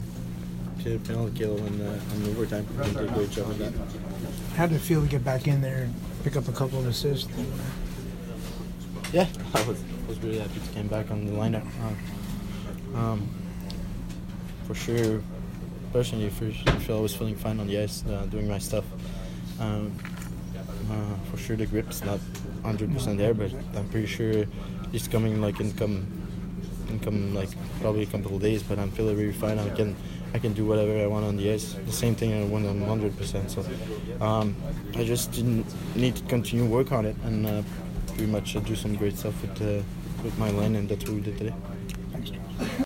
Charles Hudon post-game 3/10
Charles Hudon post-game 3/10 by Tampa Bay Lightning